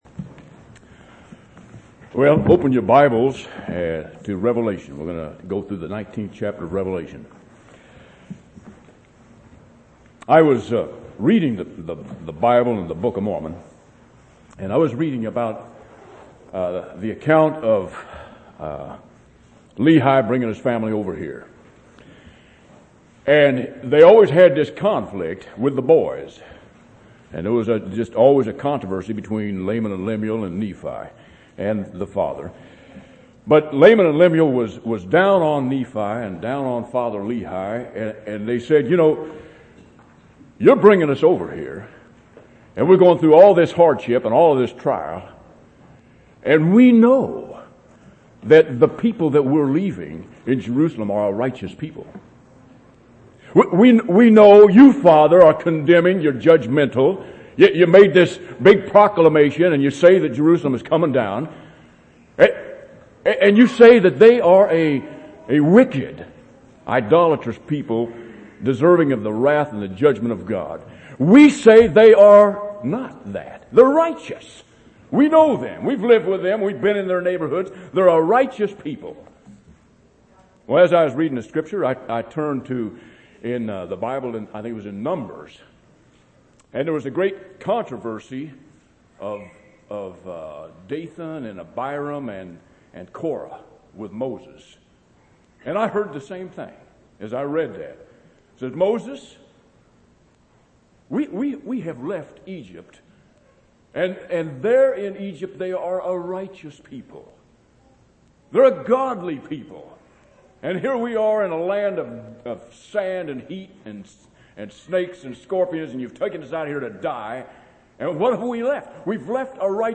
8/8/2004 Location: Temple Lot Local Event